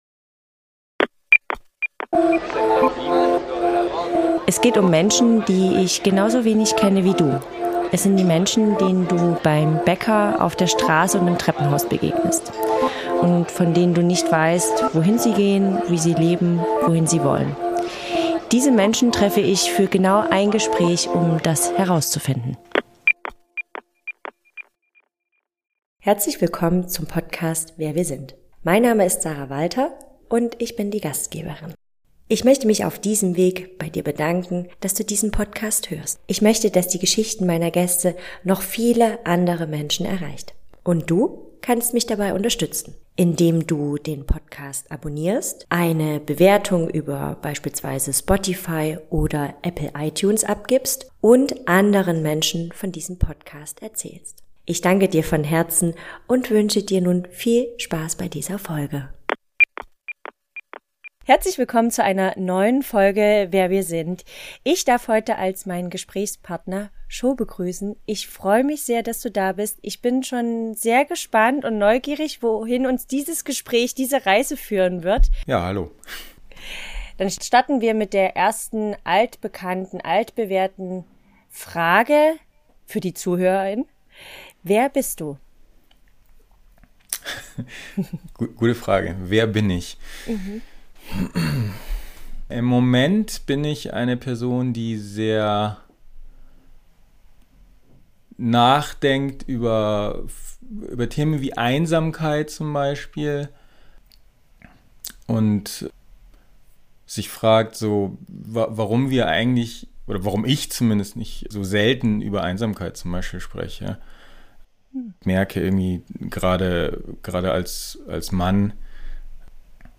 - ein Gespräch. ohne Skript. ohne Labels.